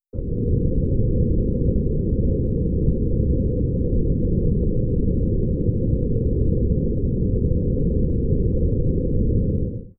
rocket.wav